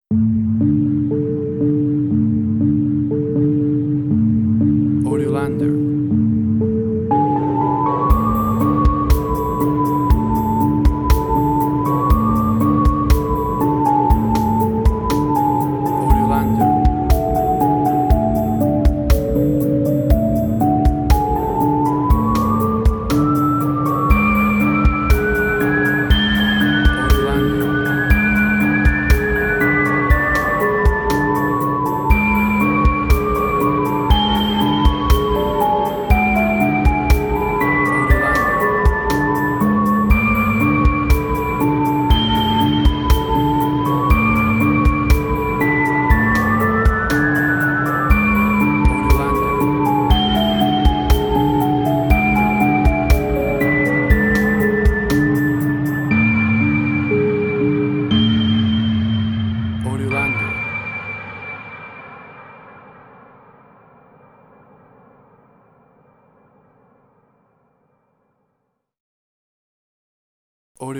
Ambient Strange&Weird
Tempo (BPM): 60